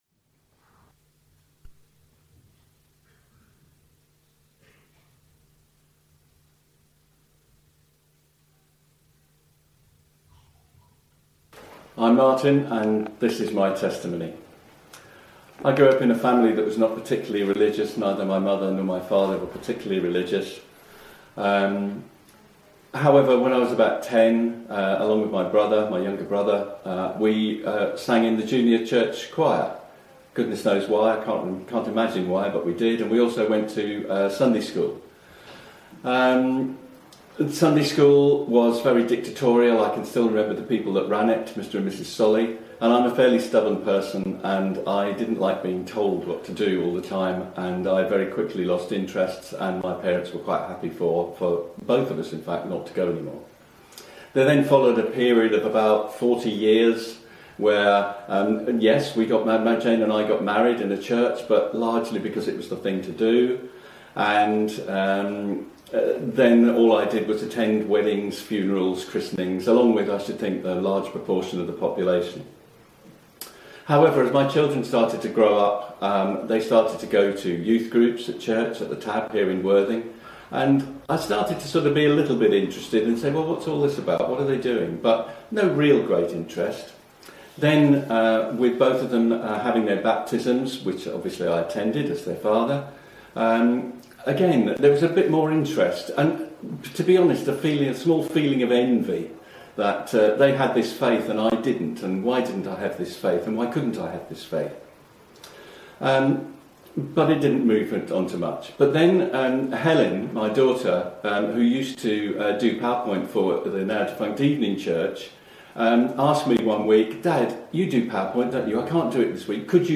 Baptism testimonies from 20th January 2013